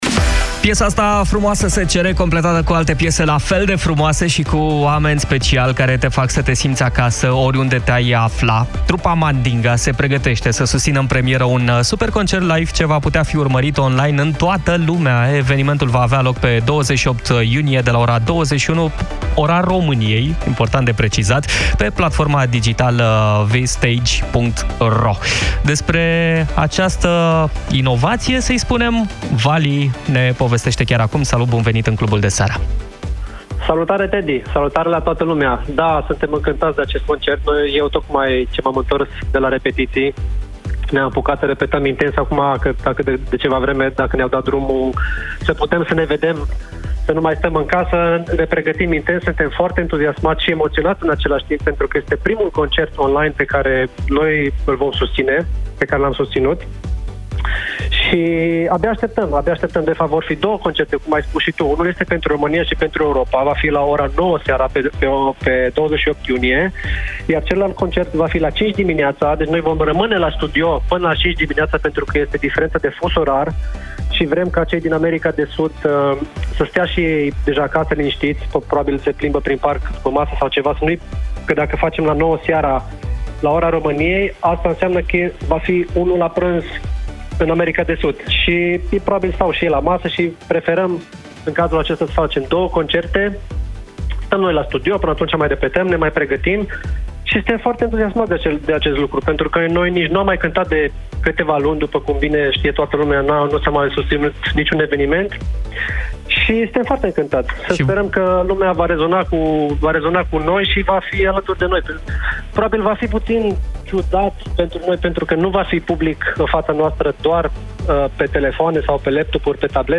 El a făcut anunțul, în direct, în Clubul de Seară – AUDIO